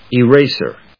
音節e・ras・er 発音記号・読み方
/ɪréɪsɚ(米国英語), ɪréɪzə(英国英語)/